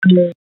ButtonSoundFX